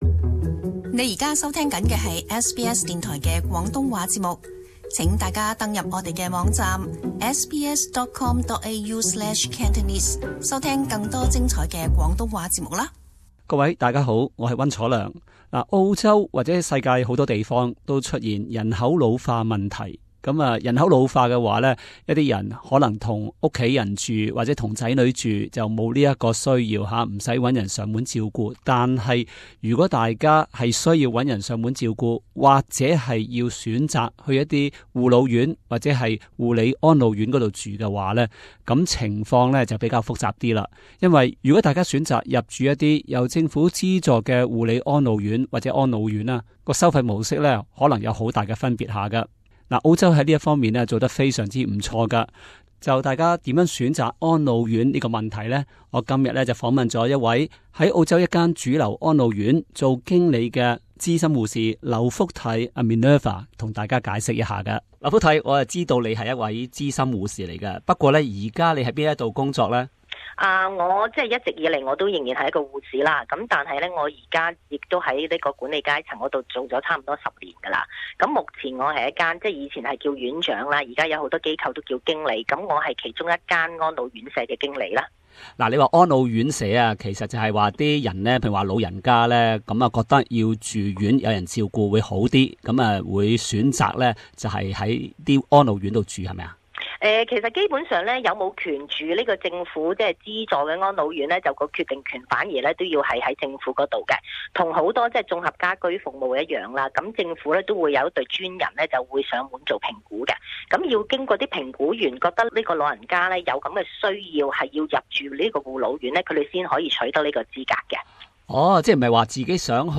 【社團專訪】